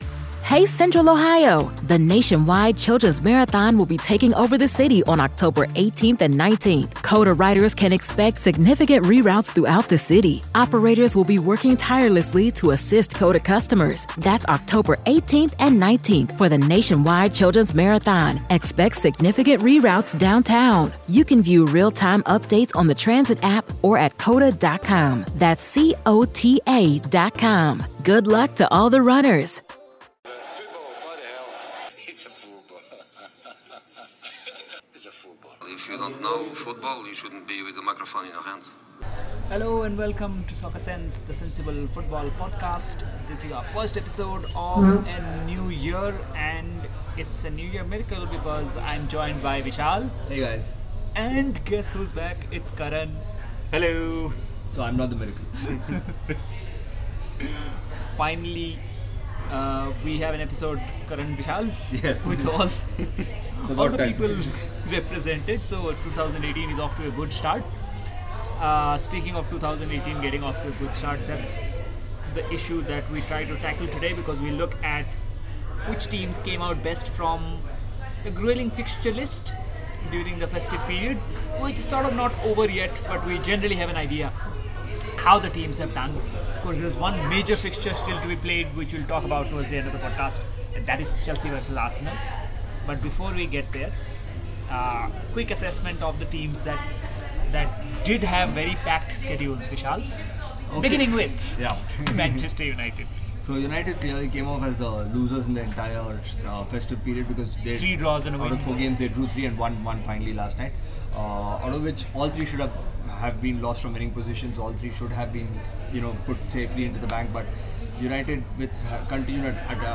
After a loooonngggg gap, all three panelists of Soccer Sense are at the same table